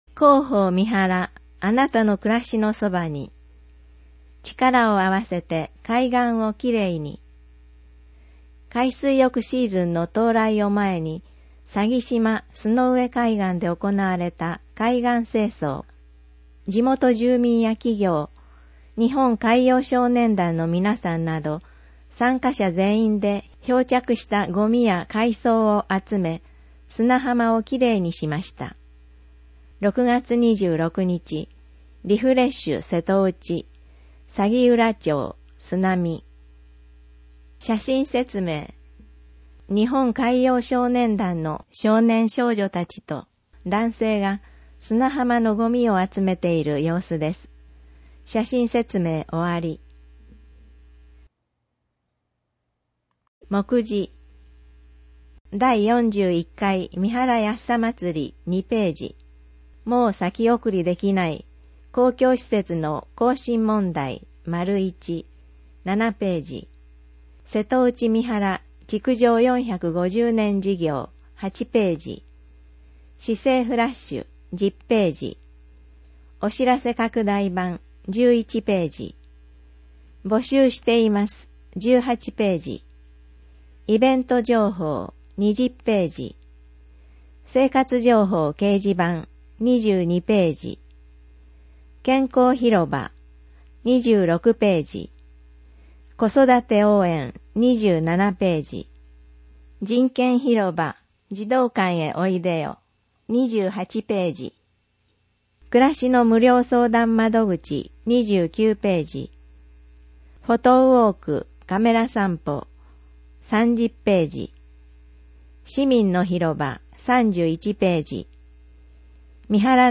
ページ タイトル 声の広報 (MP3ファイル) １－３２ 全ページ [PDFファイル／37.91MB] 約３時間１８分 [その他のファイル／45.17MB] １ 表紙 [PDFファイル／926KB] 約３分 [その他のファイル／511KB] ２－６ 第４１回三原やっさ祭り [PDFファイル／4.22MB] 約１９分 [その他のファイル／4.42MB] ７ もう先送りできない！